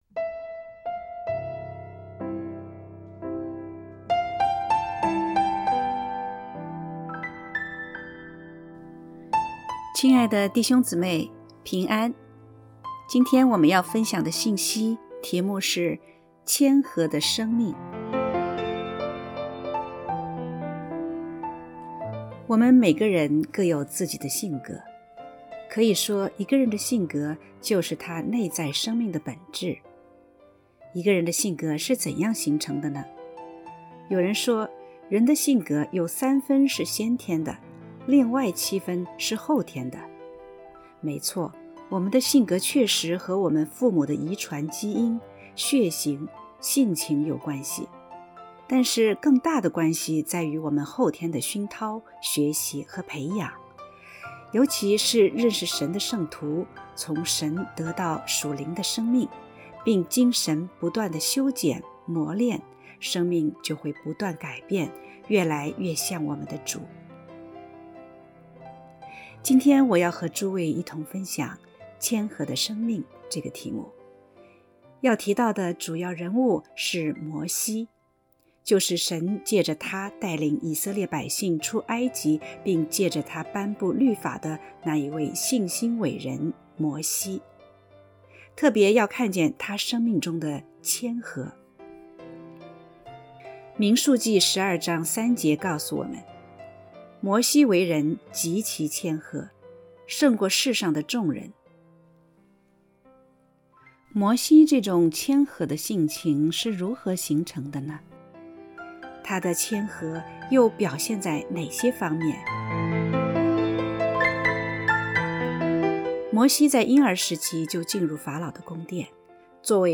（合成）谦和的生命（F3）.mp3